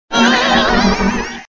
contributions)Televersement cris 4G.